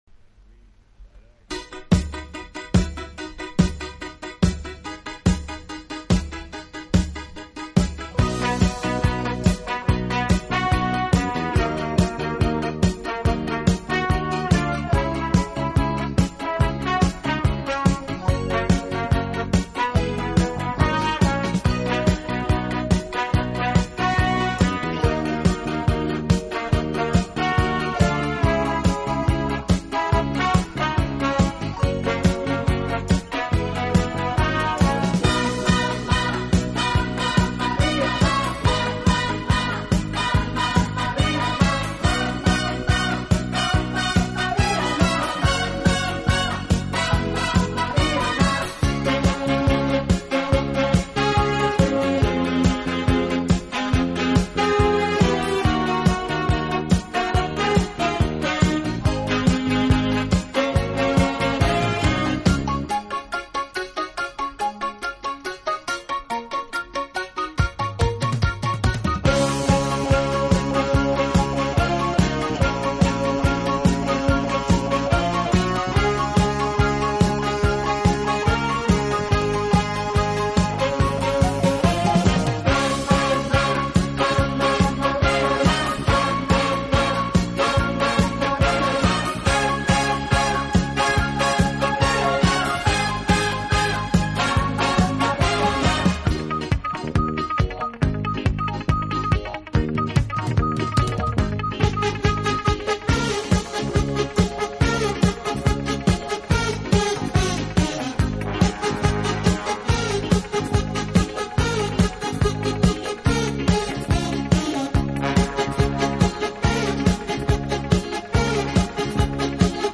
方式，尤其是人声唱颂的背景部分，似乎是屡试不爽的良药。
有动感，更有层次感；既有激情，更有浪漫。